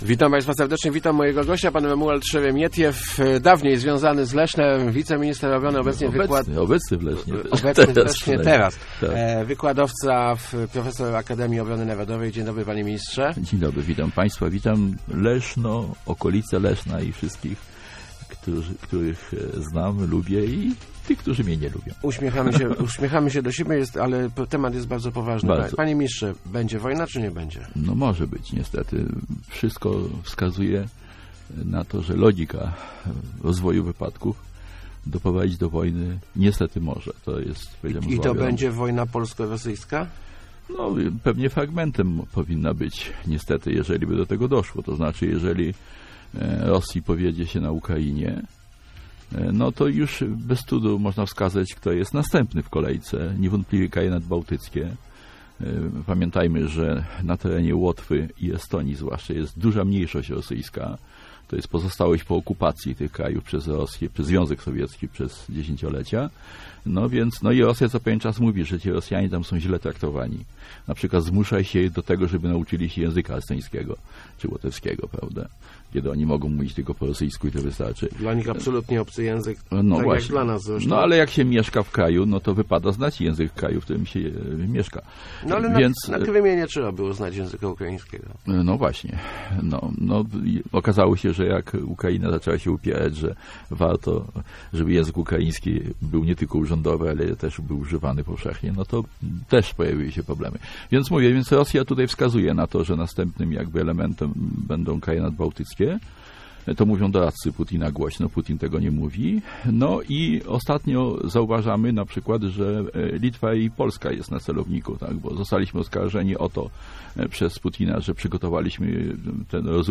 Wojna Polski z Rosją jest realnym zagrożeniem - mówił w Rozmowach Elki Romuald Szeremietiew. Były wiceminister obrony i wykładowca Akademii Obrony Narodowej zauważył przy tym, że polskie dokrtyny obronne zupełnie nie przystają do naszych czasów.